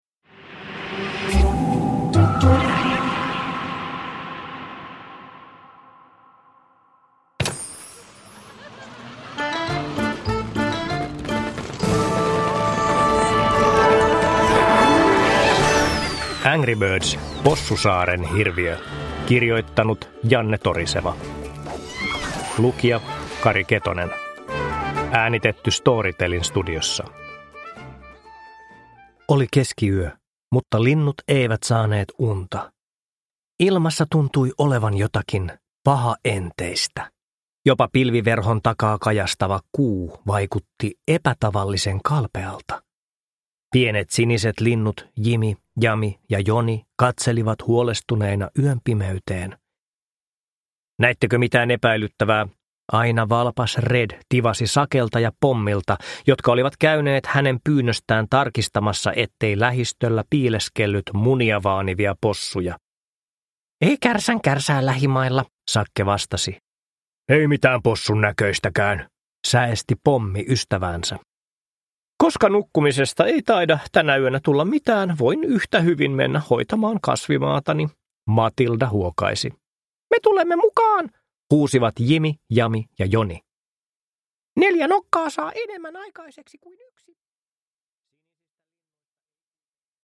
Angry Birds: Possusaaren hirviö – Ljudbok – Laddas ner